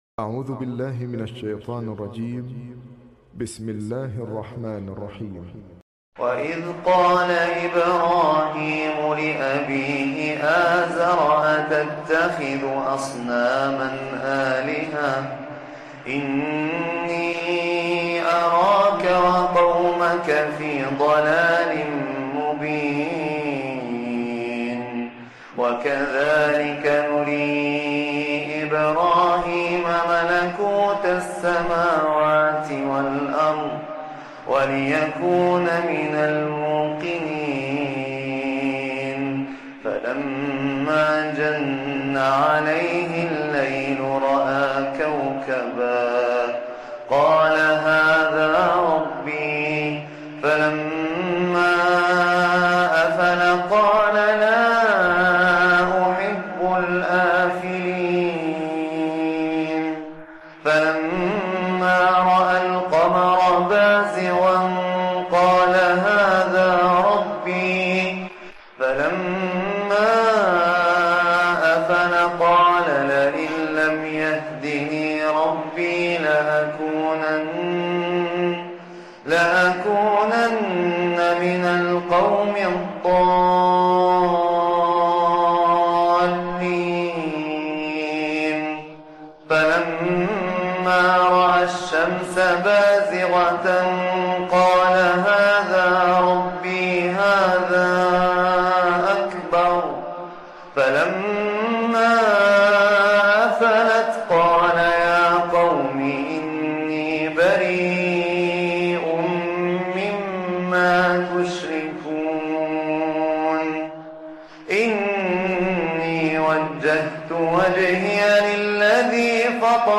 التلاوات القرآنية || تلاوة من سورة { الأنعام }